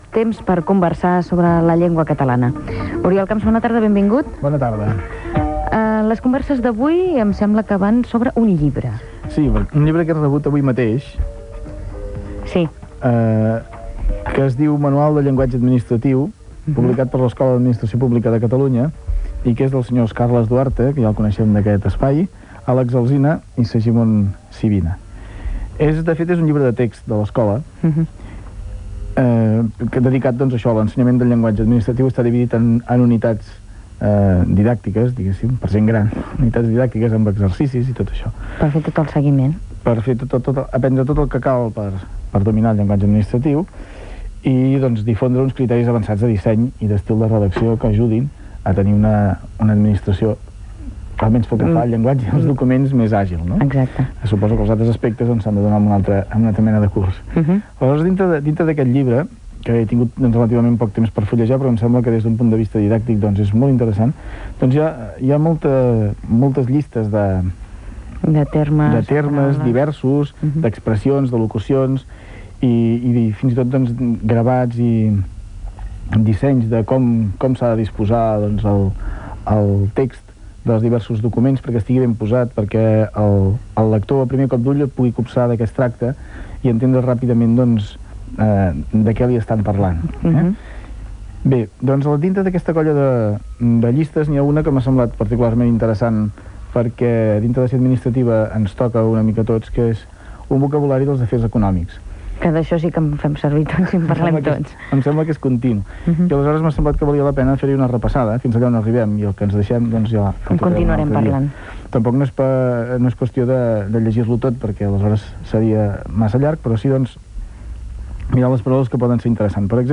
Indicatiu de l'emissora i publicitat
FM